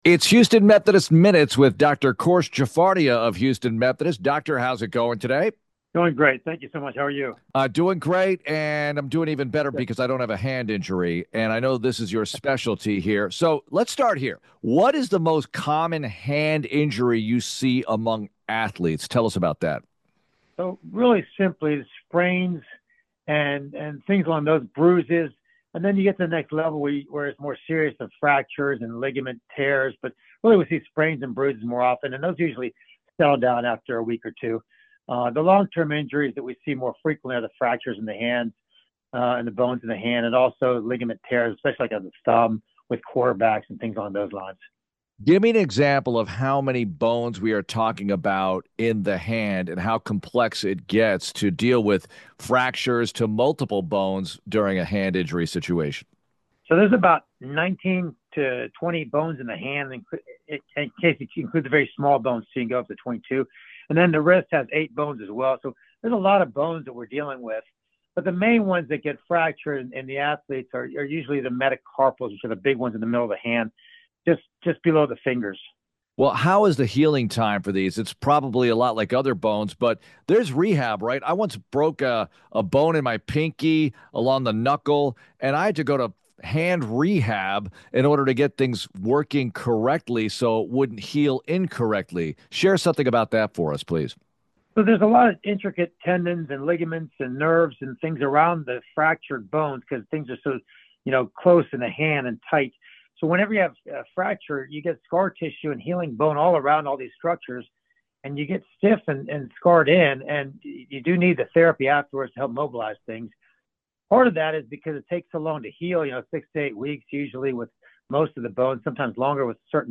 [Listen to my full discussion about hand injuries and treatments in this Houston Methodist Minutes interview]